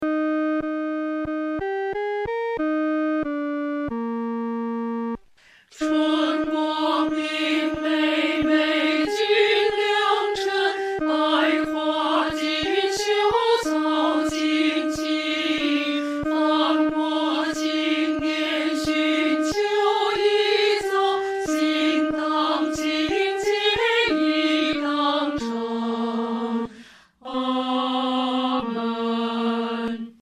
女低
本首圣诗由网上圣诗班录制